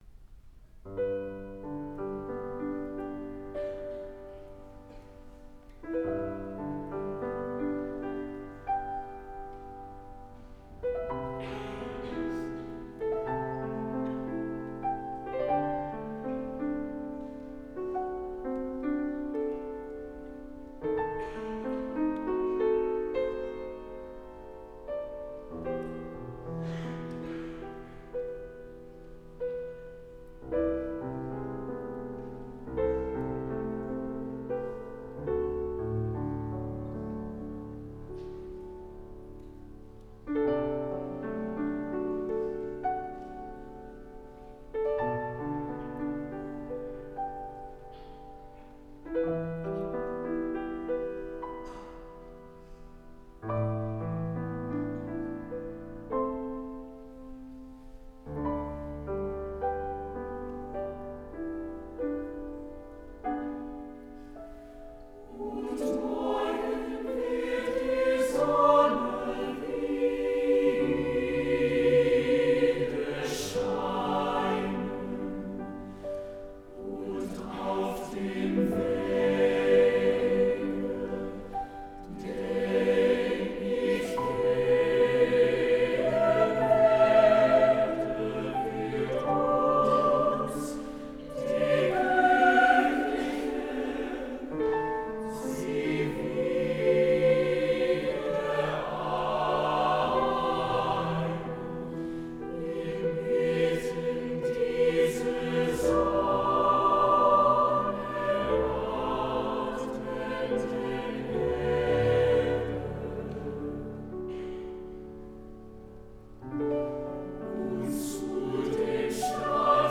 SATB & piano
at Brigham Young University, Provo, UT.